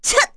Chrisha-Vox_Attack3_kr.wav